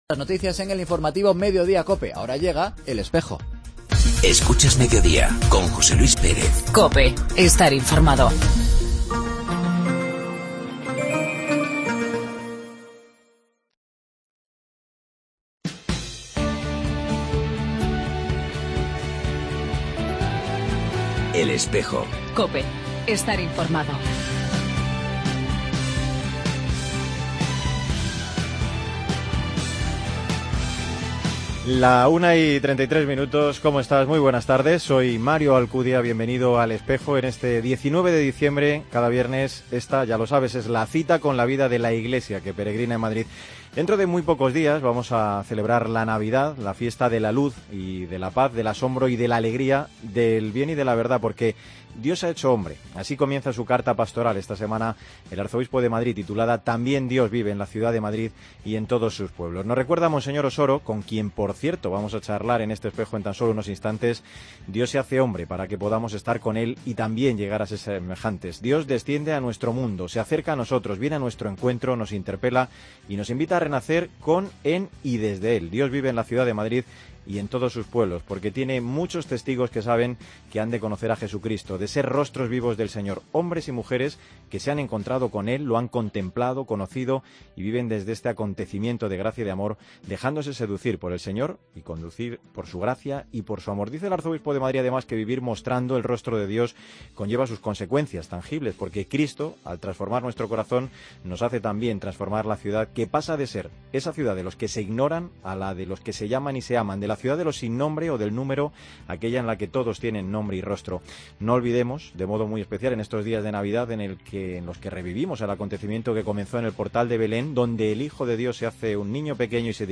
AUDIO: Entrevista al arzobispo de Madrid en su primera Navidad como Pastor de esta Iglesia que peregrina en Madrid y Campañas de Navidad de la...